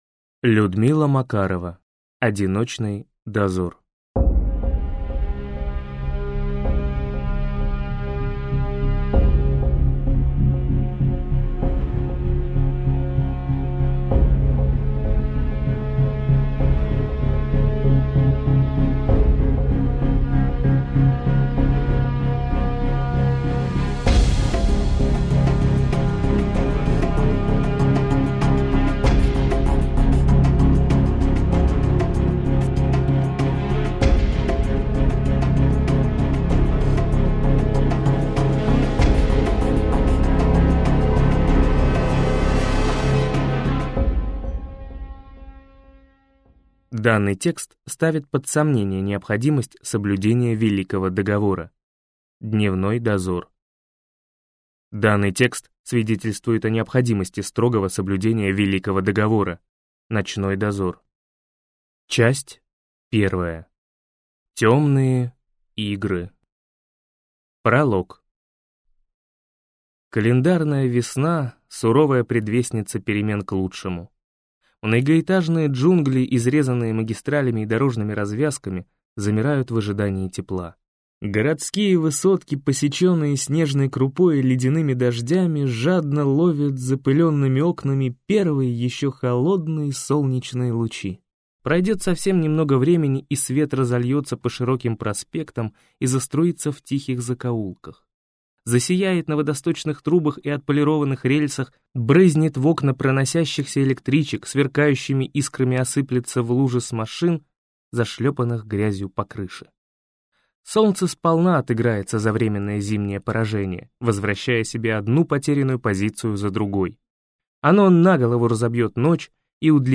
Аудиокнига Одиночный Дозор | Библиотека аудиокниг